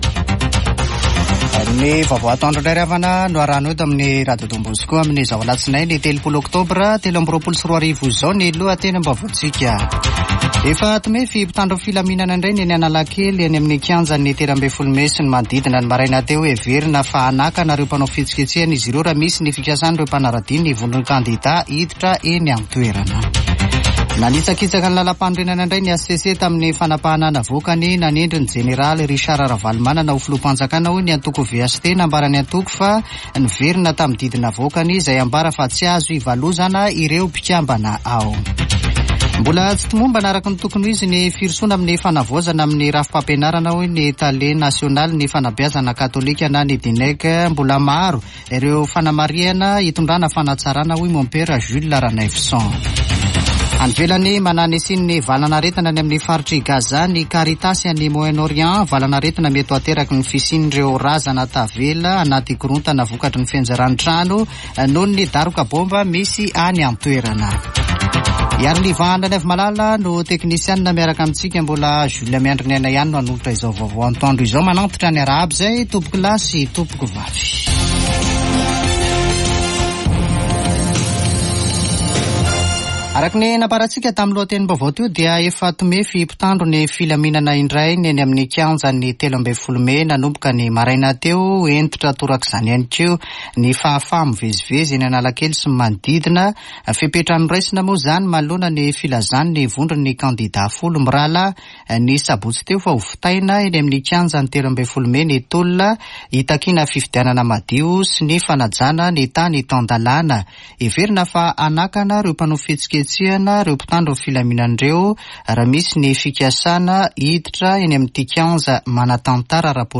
[Vaovao antoandro] Alatsinainy 30 ôktôbra 2023